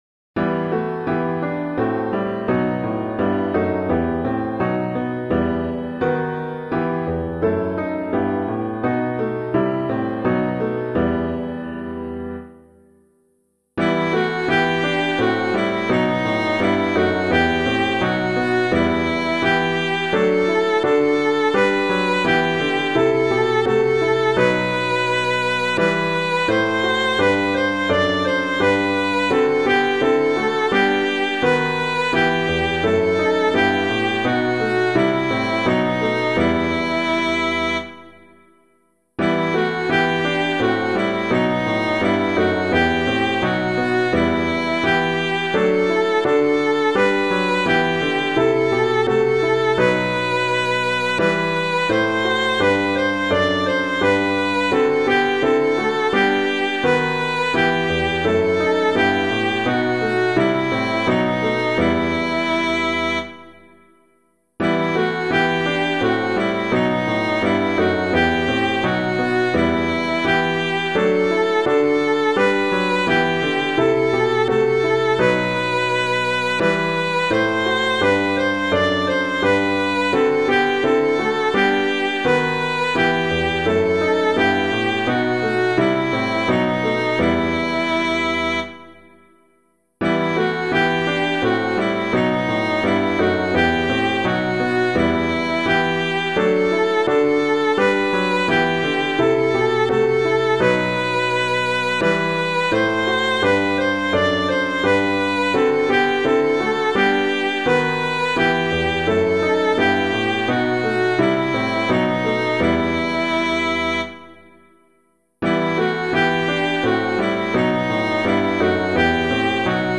piano
O Christ the Healer We Have Come [Green - ERHALT UNS HERR] - piano.mp3